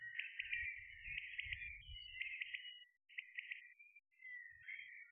[Identifié] Chant d'oiseau - Caille des blés
chant d'oiseau
Je l'entends assez souvent dans les hautes herbes. 4-5 tut-tutut répétés toutes les 2-3 minutes.
C'est la caille des blés